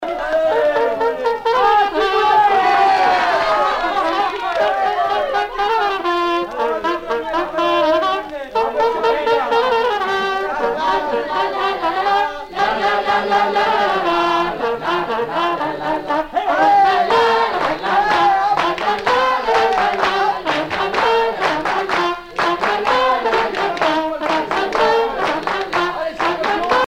Airs traditionnels de noces poitevine - Air de polka
danse : polka
Pièce musicale éditée